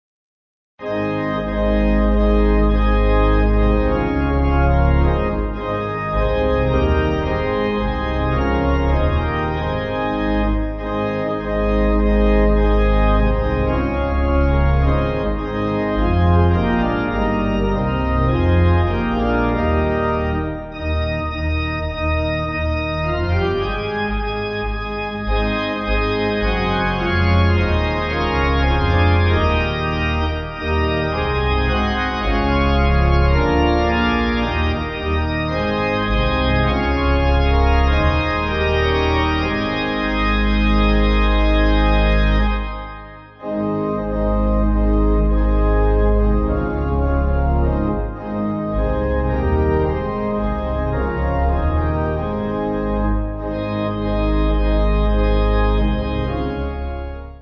8.8.8.8.D
Organ
(CM)   3/Ab